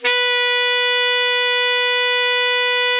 Index of /90_sSampleCDs/Giga Samples Collection/Sax/GR8 SAXES MF
ALTO  MF B 3.wav